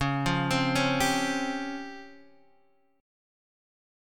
C#M#11 chord